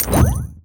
vanish_spell_flash_potion_01.wav